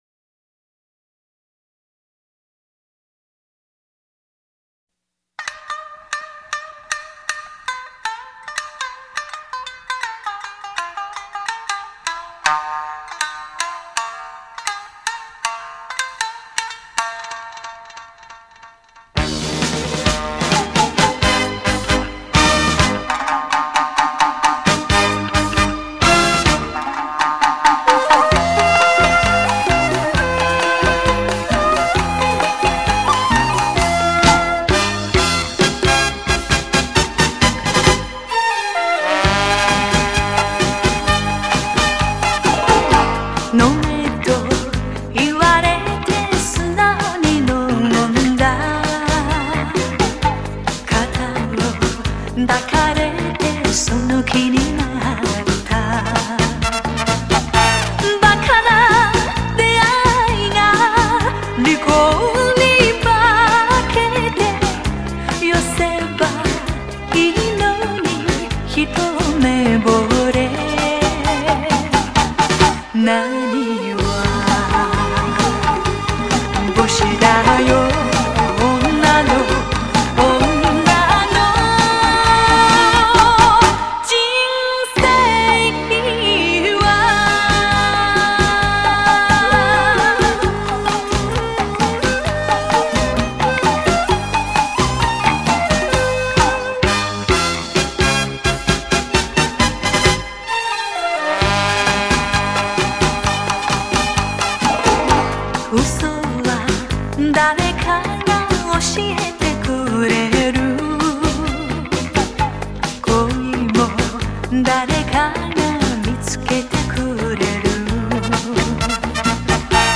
脍炙人口怀念金曲
温馨甜蜜耐人寻味